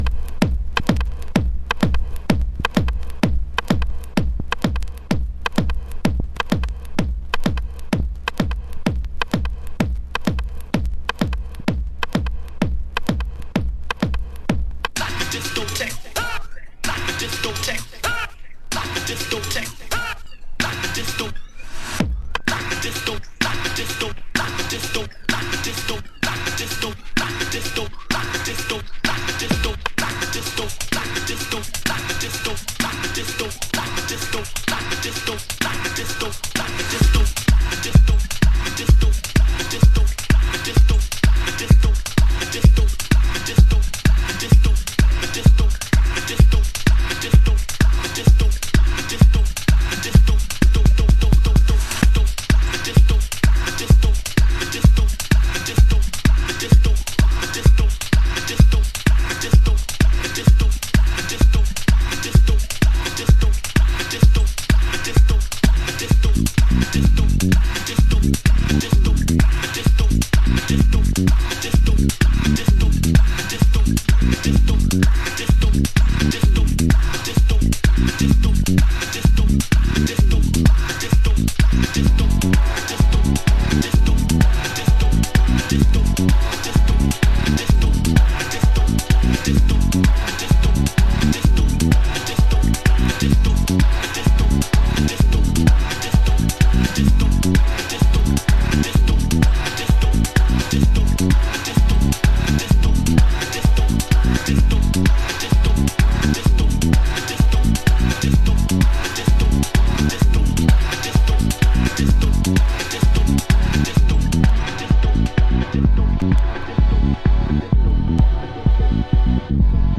Early House / 90's Techno
疾走するドラミングとアップリフティングなシンセワークが明朗闊達にグルーヴ。